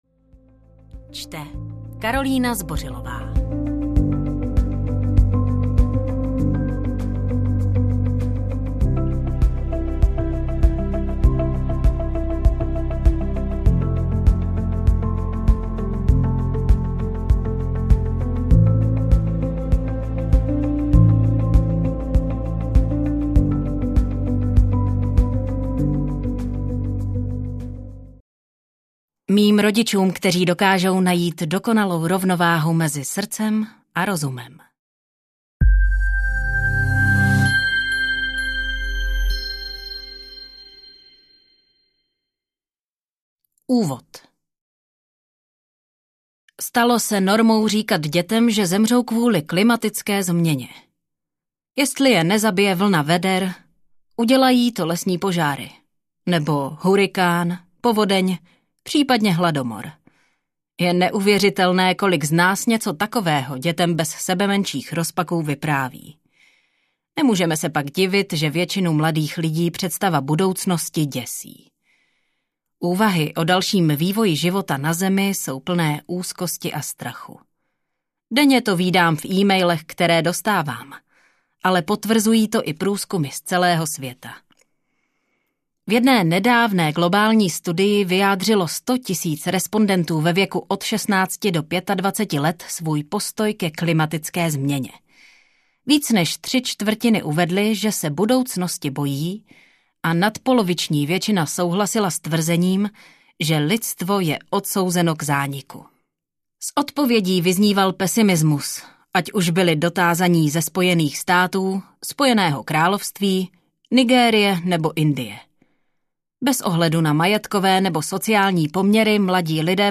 Není to konec světa audiokniha
Audiokniha Není to konec světa, kterou napsala Hannah Ritchie. Přepadá vás někdy úzkost ze stavu naší planety?
Ukázka z knihy